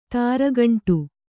♪ tāragaṇṭu